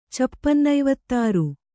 ♪ cappannaivattāru